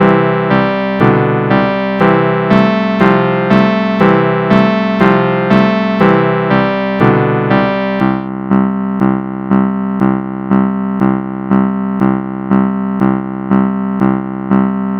This is a small and rather crude Futhark library containing combinators for describing sounds, which can then be sampled to produce audio files.